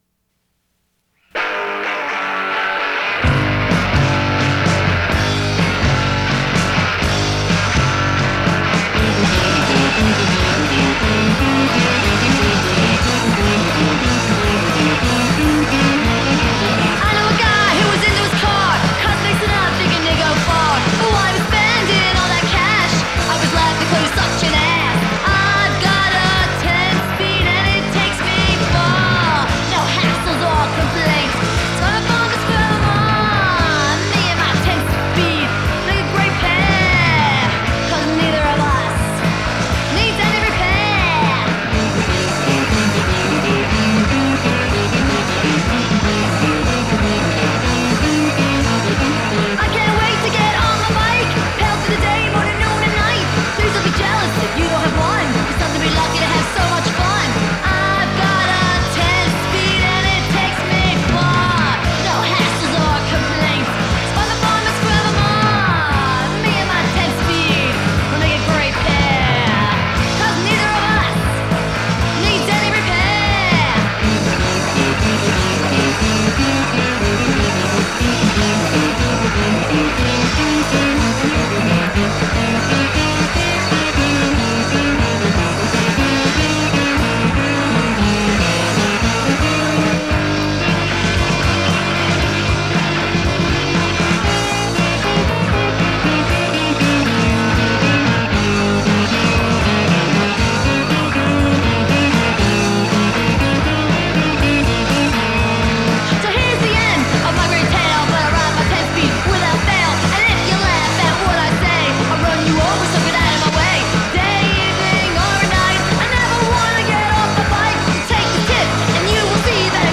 Philadelphia all-girl punk band from the late 80s
5 song demo tape